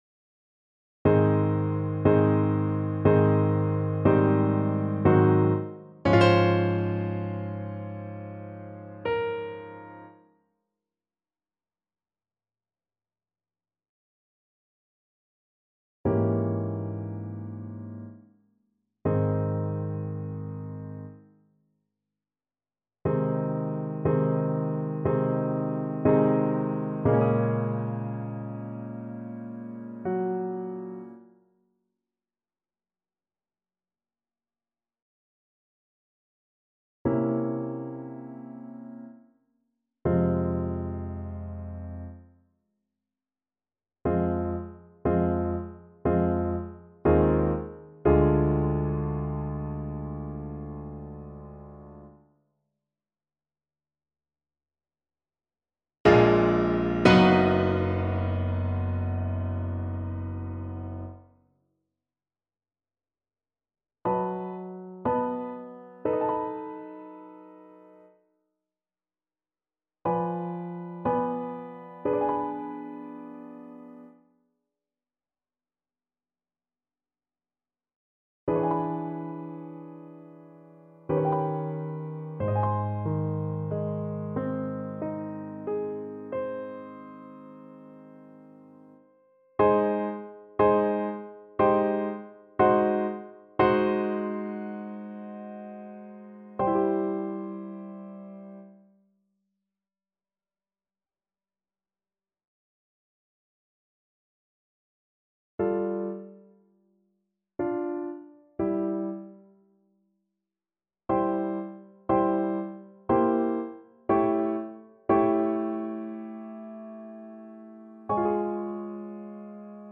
~ = 60 Langsam, leidenschaftlich
3/4 (View more 3/4 Music)